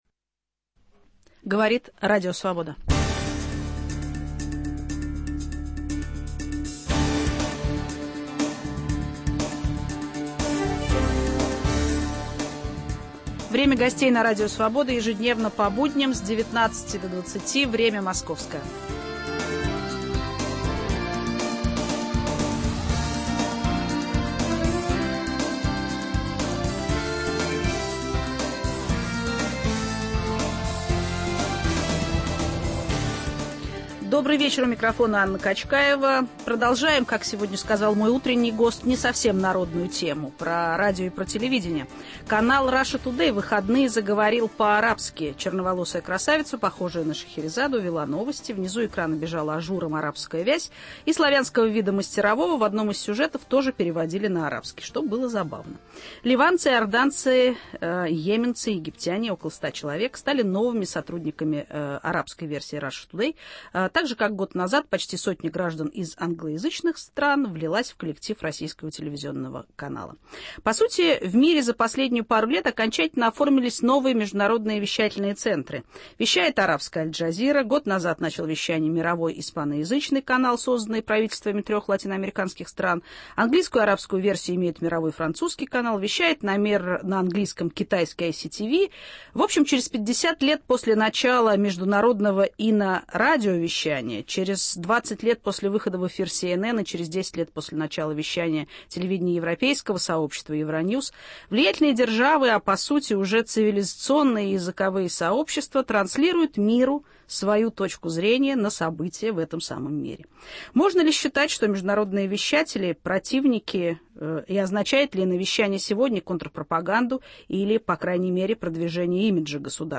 Глобальные СМИ: cоздатели внешнеполитических образов или новый инструмент публичной дипломатии? Гость: главный редактор телеканала "Russia Today" Маргарита Симонян.